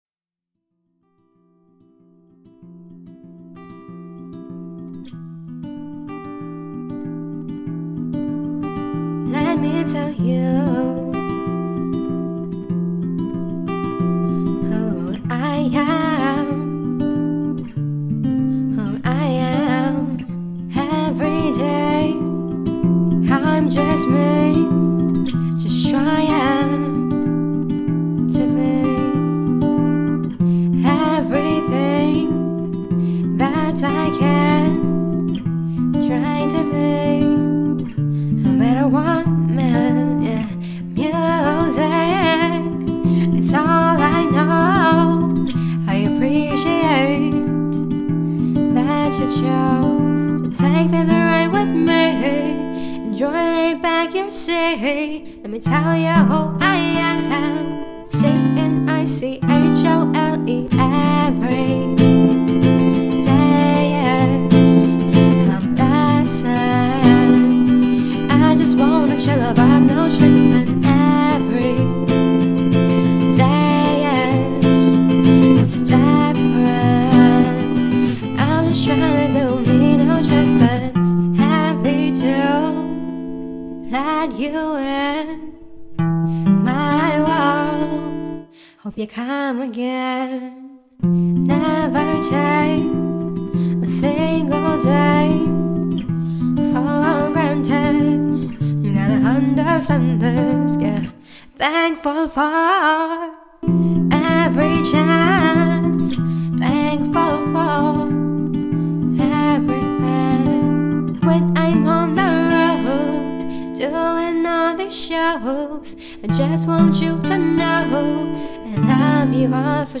Genres: Acoustic, R&B